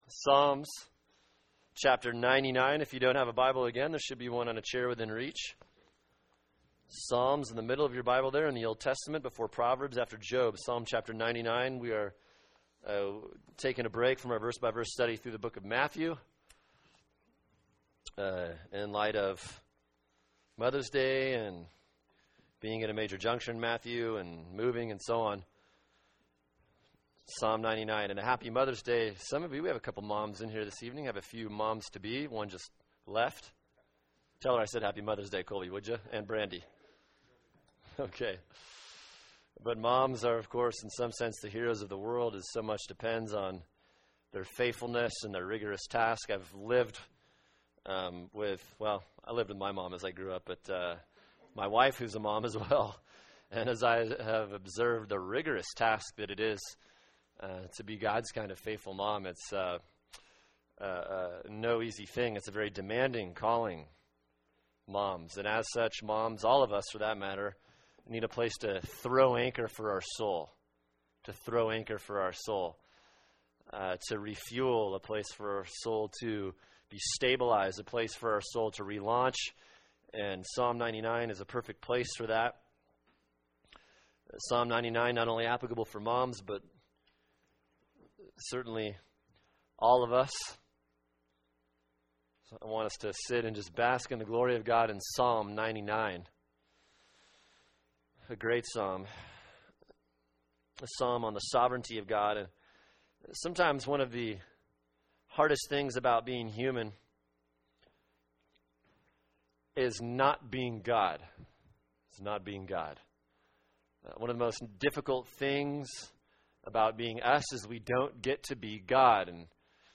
[sermon] Psalm 99 “The Anchor for Your Soul” | Cornerstone Church - Jackson Hole